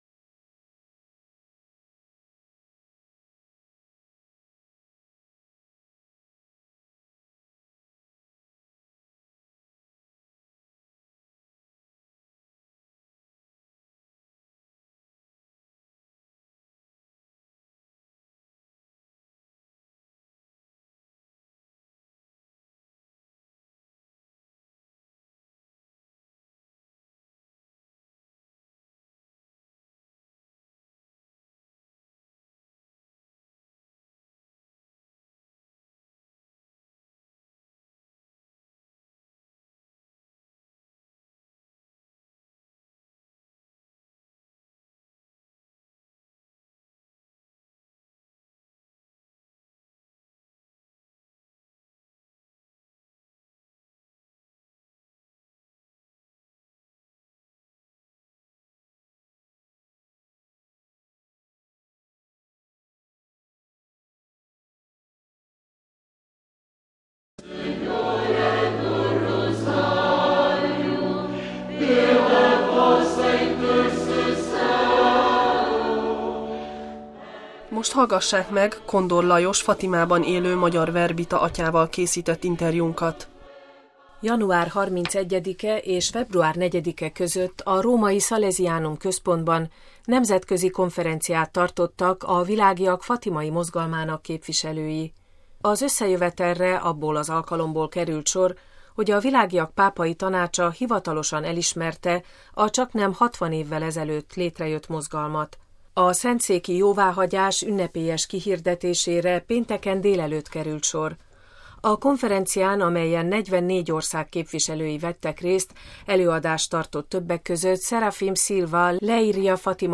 interjú